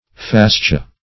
Fascia \Fas"ci*a\, n.; pl.